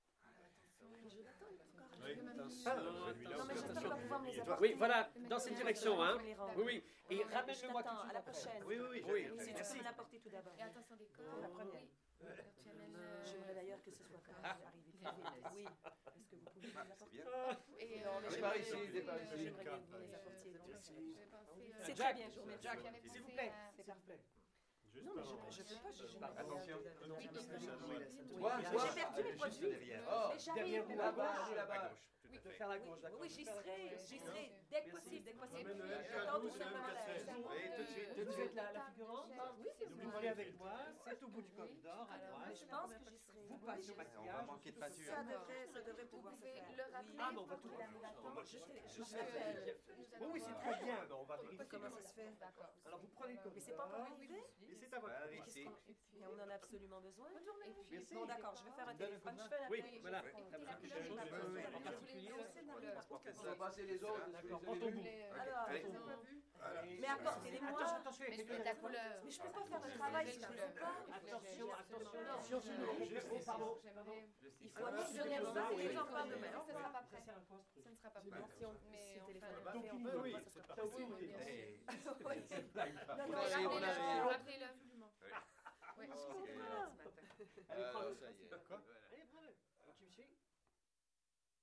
描述：室内声乐（法国）氛围：电视演播室声乐氛围
Tag: 沃拉 定位资产 电视演播室 声乐氛围 室内